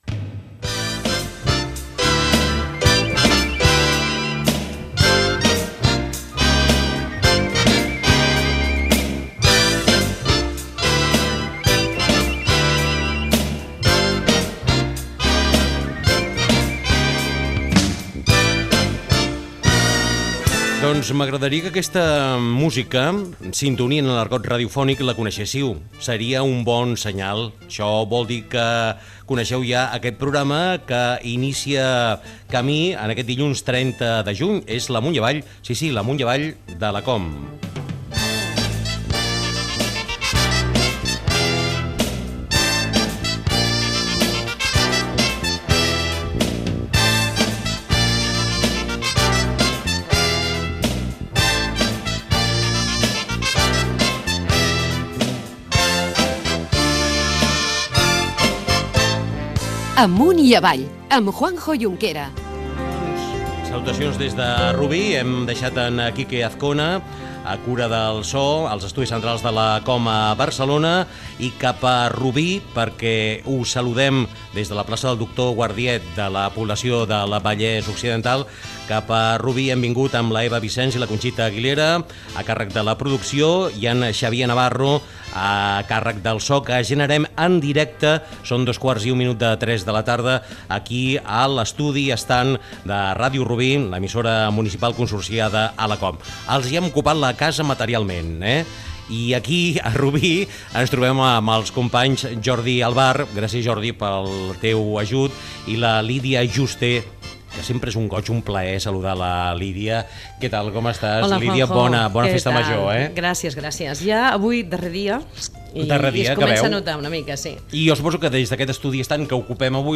Sintonia, inici i crèdits del programa des de Rubí, amb motiu de la festa major.
Entreteniment